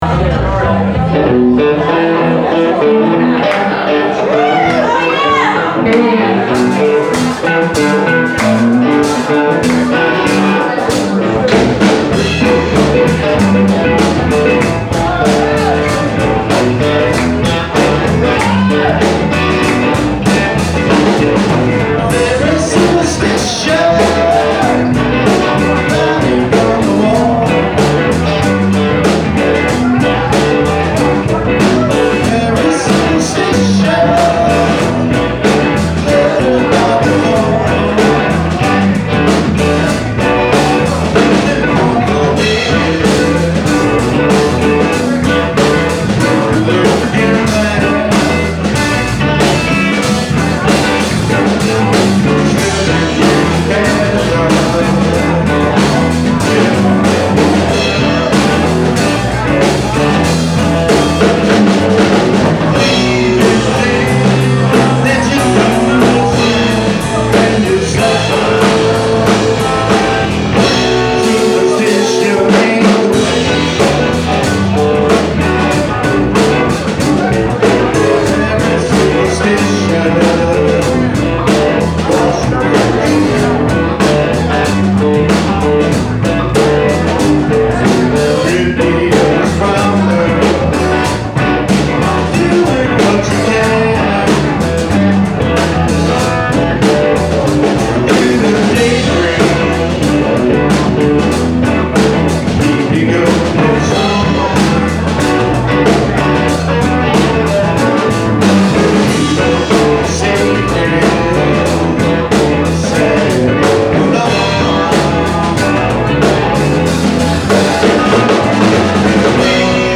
from a camcorder on a tripod